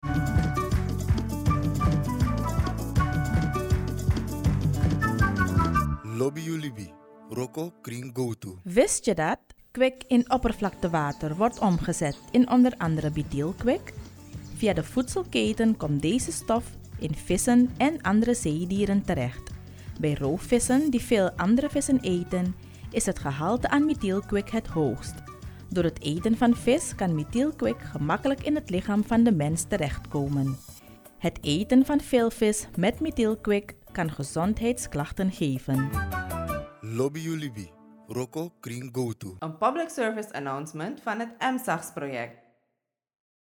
EMSAGS NL Radiospot 6